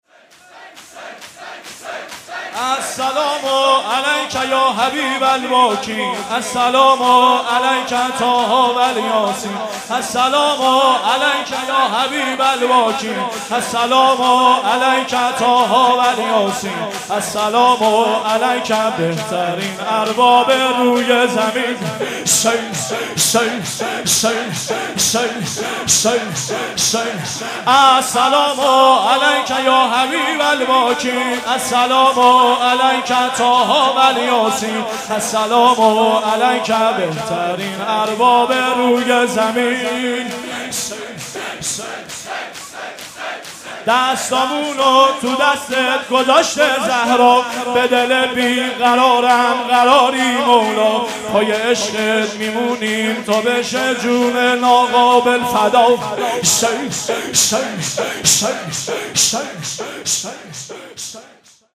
مداحی
بوی جدایی میرسه از این دیار آقا (واحد)
پریشونه غم توام ارباب (شور)
(زمینه)